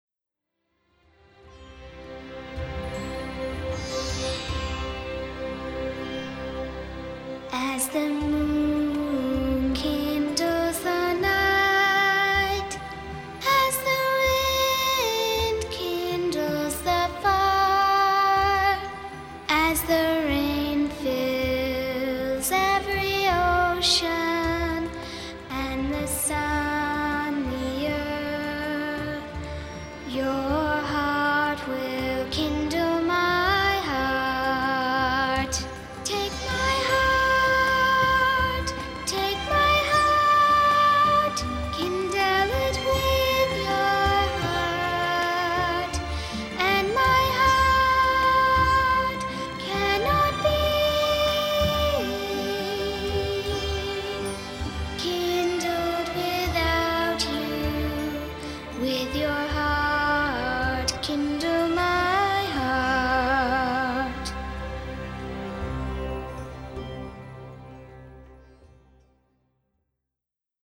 full of heartfelt themes, lilting waltzes, Indian flourishes